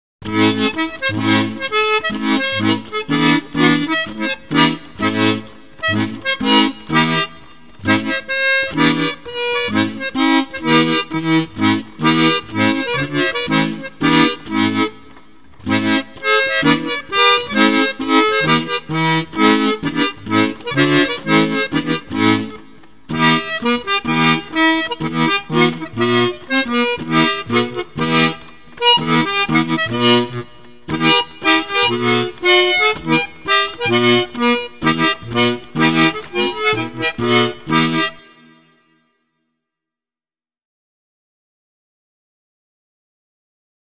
Del primer punto no vamos a tratar, pues excede los objetivos de estas lneas, ms all de resaltar la correcta toma de sonido que provoca que casi parezca que tenemos las cautivadoras cajitas musicales en la propia sala donde la msica se reproduce, incluyendo tambin, cuando sucede, los ruidos procedentes de los propios engranajes que posibilitan la creacin del sonido.